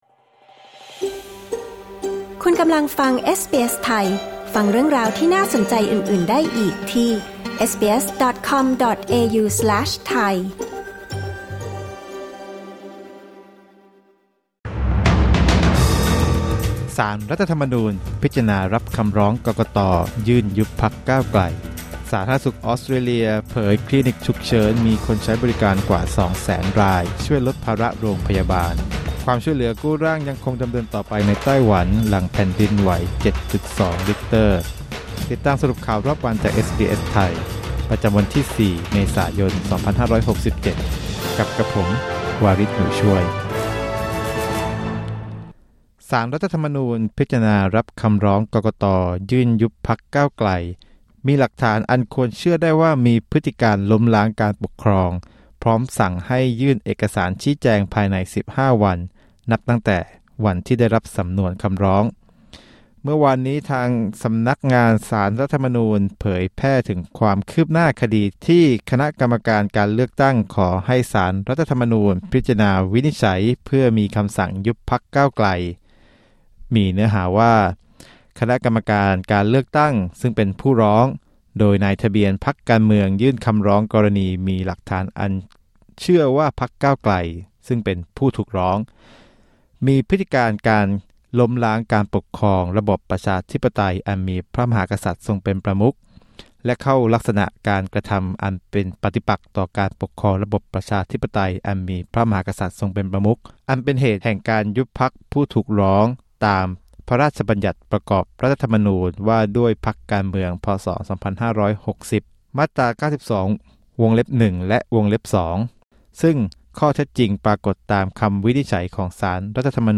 สรุปข่าวรอบวัน 4 เมษายน 2567